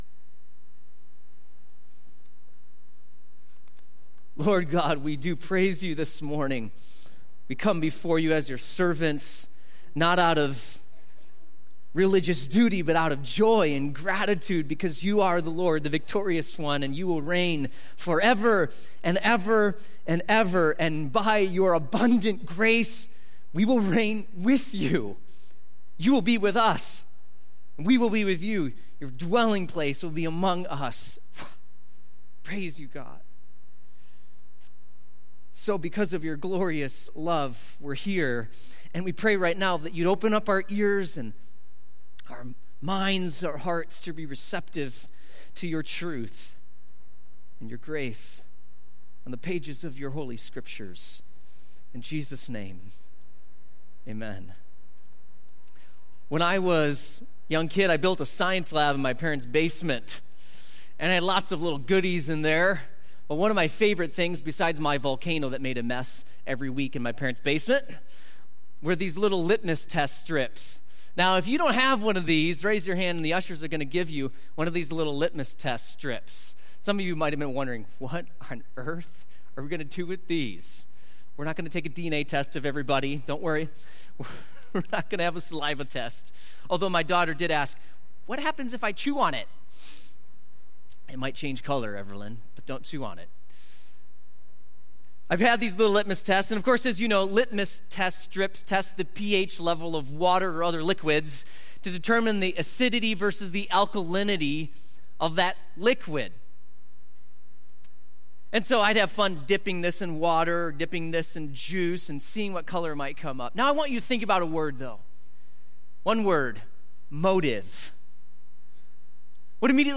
Sermons - Mayfair Bible Church